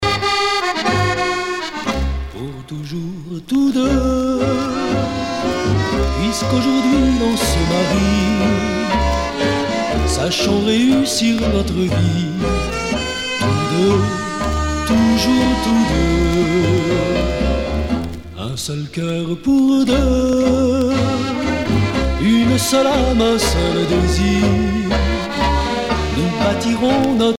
danse : tango musette
Pièce musicale éditée